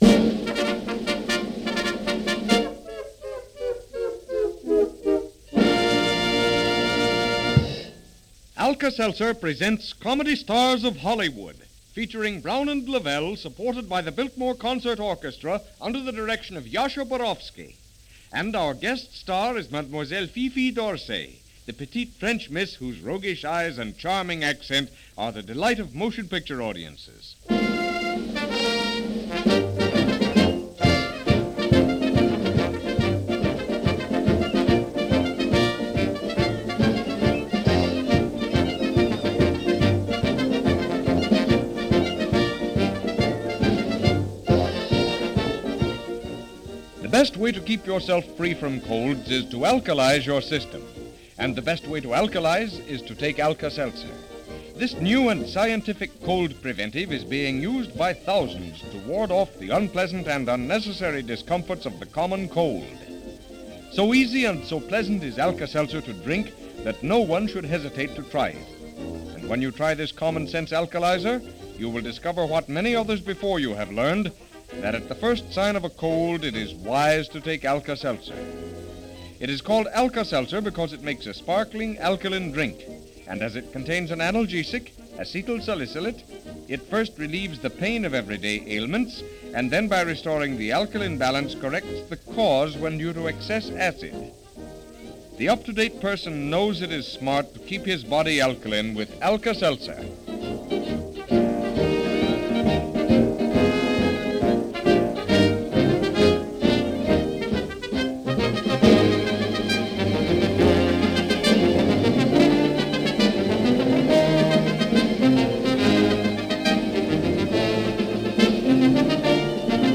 1932 - Jazz Age Radio - Vaudeville And Fifi D'Orsay - radio during the early days when vaudeville claimed the medium - Past Daily Archeology.